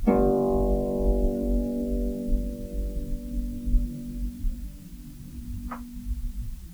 KSHarp_G1_mp.wav